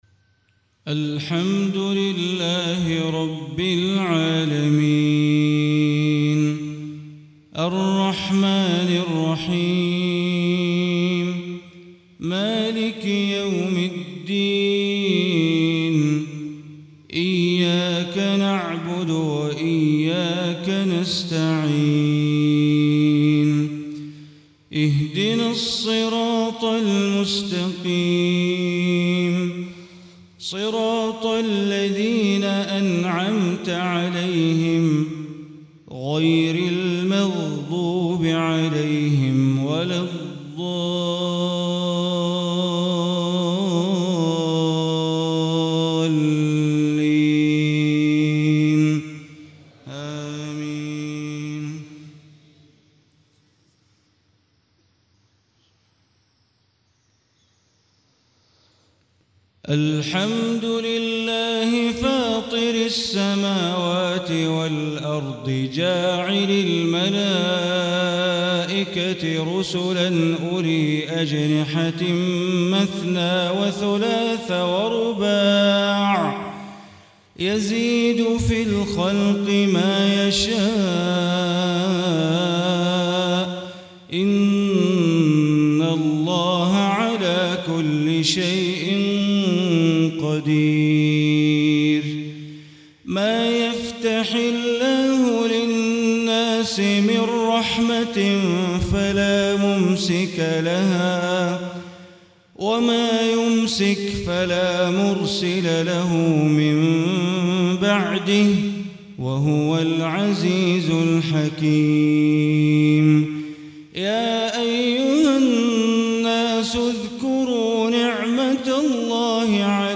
صلاة الفجر 1 - 2 - 1436 تلاوة من سورة فاطر > 1436 🕋 > الفروض - تلاوات الحرمين